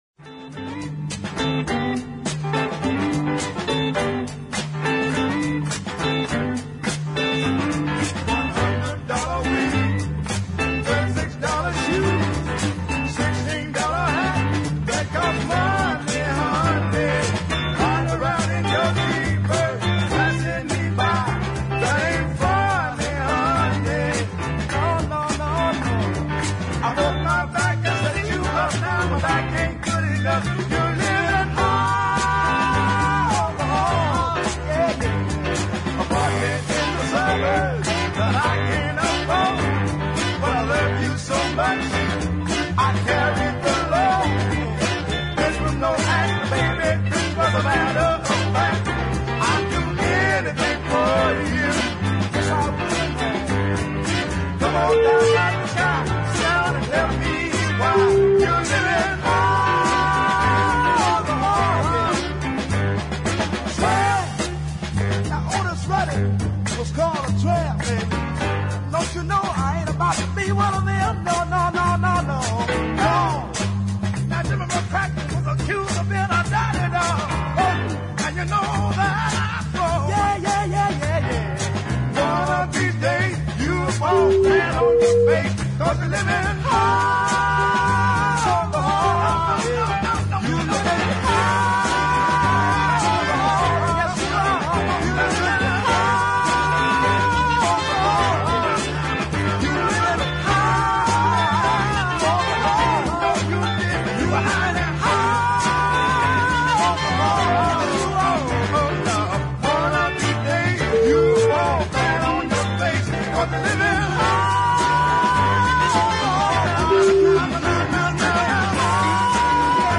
two pacy pieces of fairly funky soul